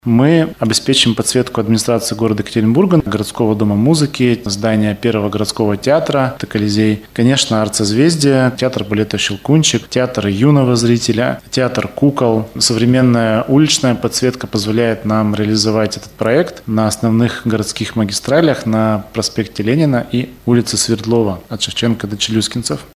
2 апреля, во Всемирный день распространения информации о проблеме аутизма, здания в городе будут подсвечены синим цветом. Об этом рассказал директор городского департамента культуры Илья Марков на пресс-конференции «ТАСС-Урал».